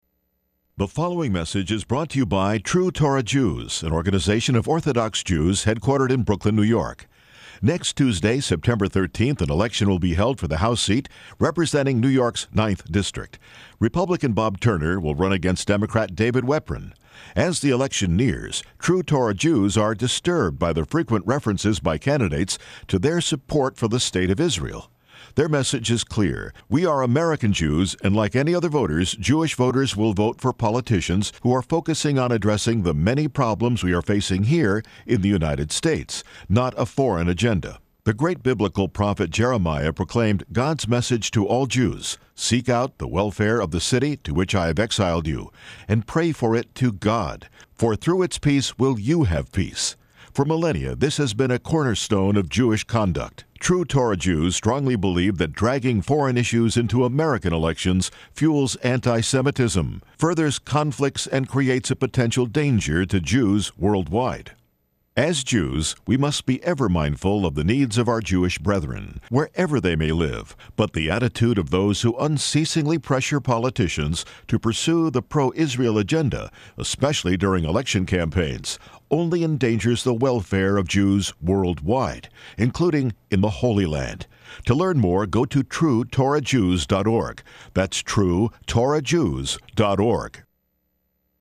The following is the text of a radio ad by True Torah Jews, which will run on WABC New York on Sunday, Monday and Tuesday, September 11, 12 and 13:
Radio Ads